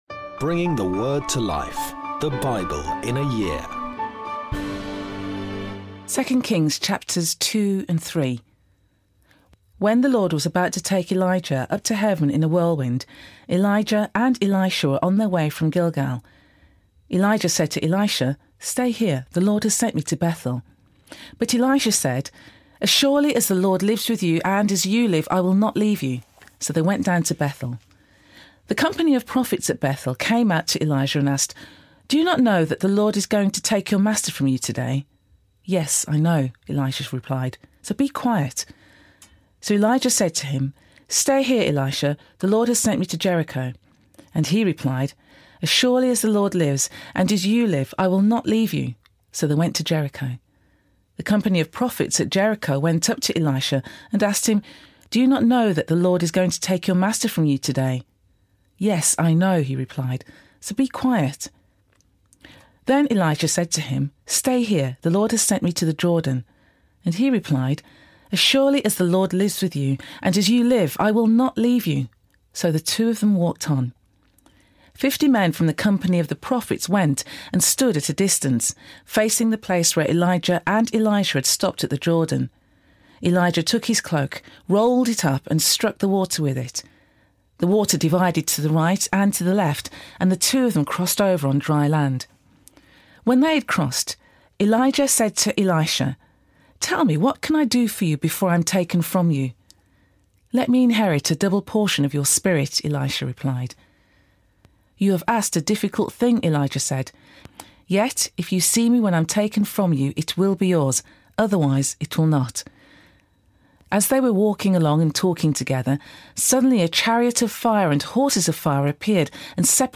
Today’s readings come from 2 Kings 2-3; Mark 4 Sponsored ad Sponsored ad